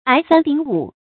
捱三頂五 注音： ㄞˊ ㄙㄢ ㄉㄧㄥˇ ㄨˇ 讀音讀法： 意思解釋： 形容人群擁擠，接連不斷 出處典故： 明 馮夢龍《醒世恒言 賣油郎獨占花魁》：「覆帳之后，賓客如市， 捱三頂五 ，不得空閑。」